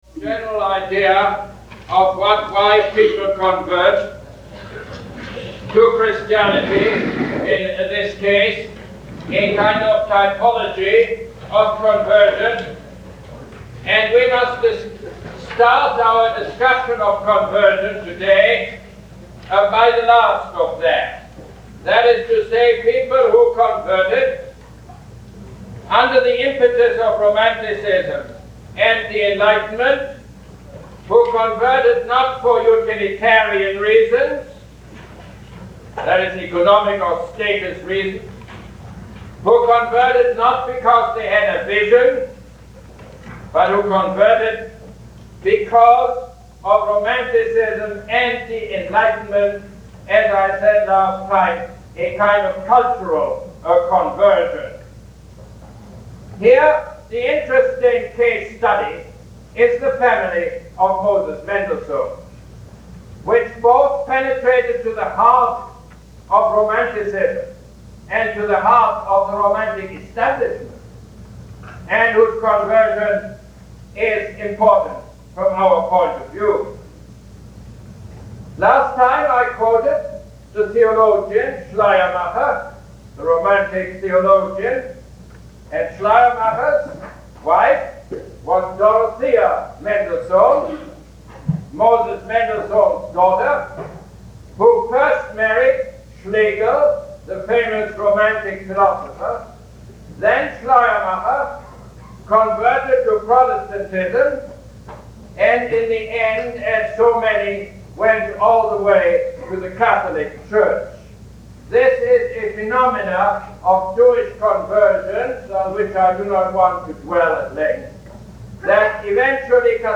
Lecture #6 - February 24, 1971